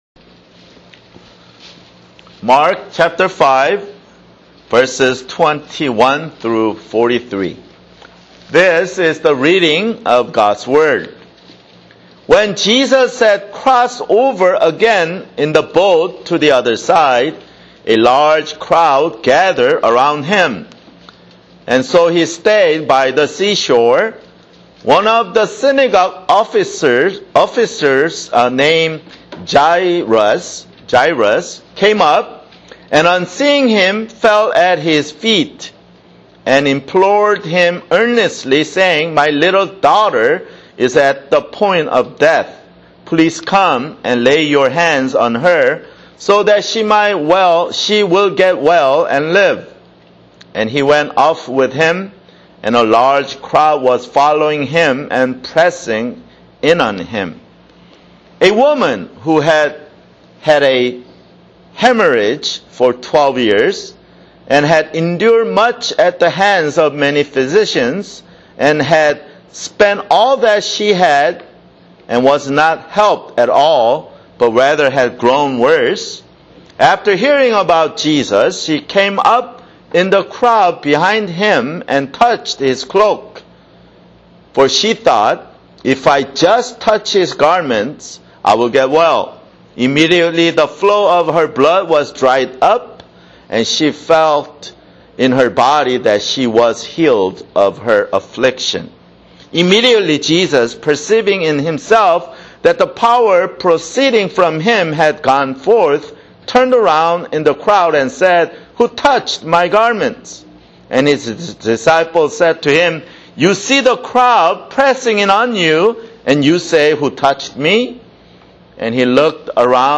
[Sermon] Mark 5:21-43
Mark 5:21-43 (Lord's Supper)